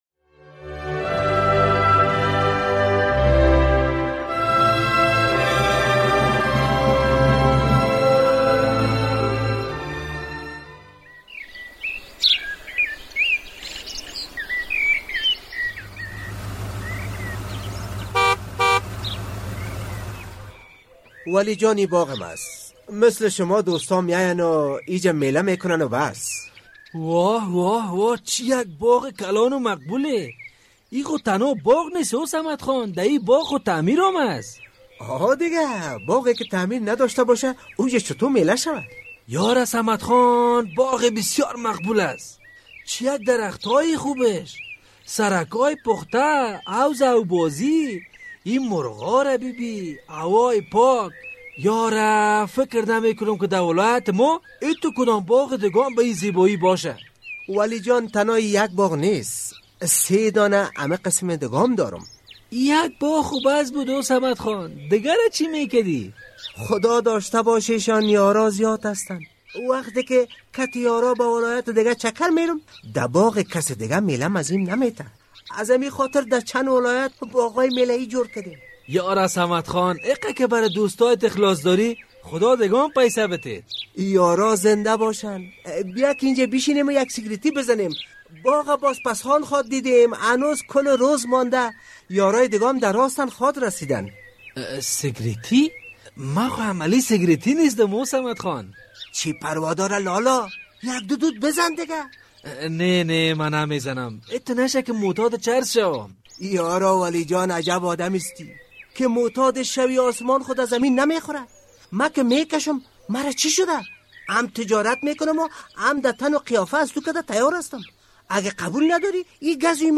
درامه؛ ۱۰ سال چرس زدن چرا به صمد خان زیان نرسانده است؟